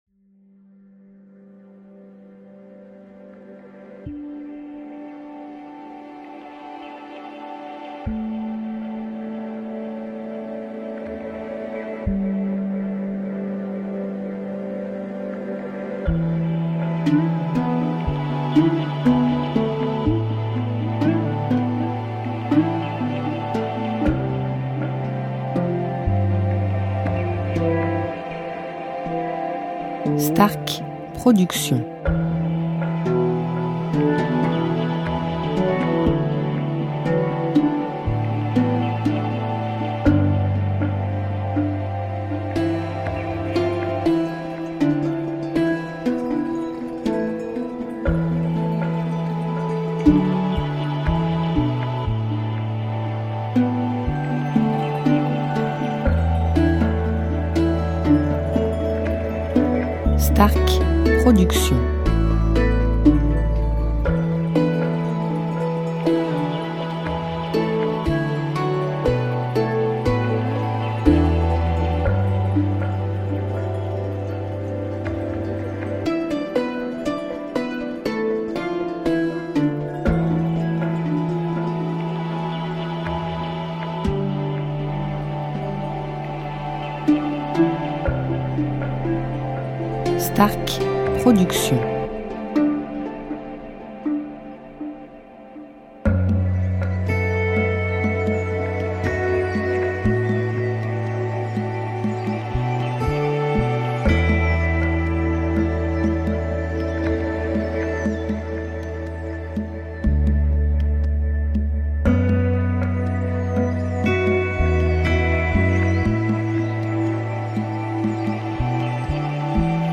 style Californien durée 1 heure